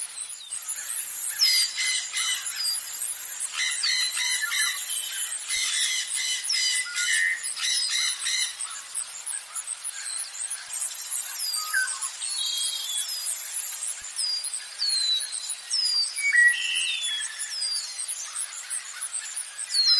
Crickets Night
Crickets Night is a free nature sound effect available for download in MP3 format.
# crickets # night # insects About this sound Crickets Night is a free nature sound effect available for download in MP3 format.
334_crickets_night.mp3